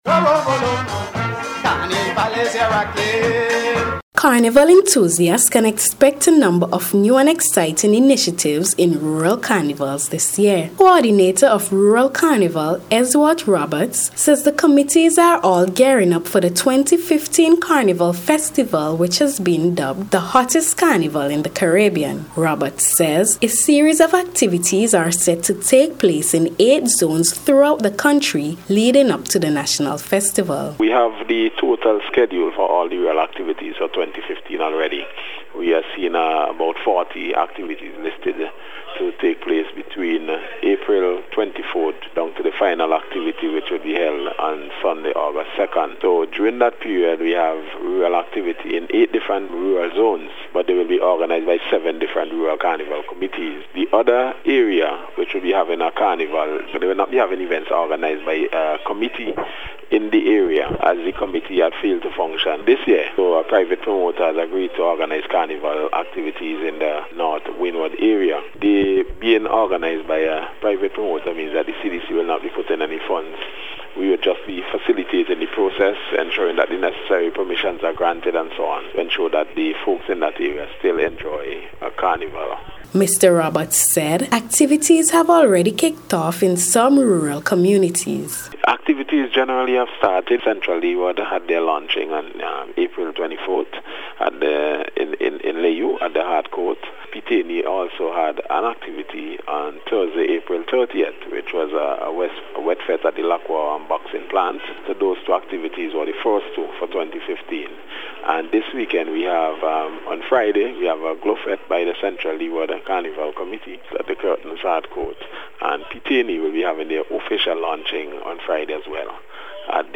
RURAL-CARNIVALS-REPORT-.mp3